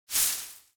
grass swish 4.ogg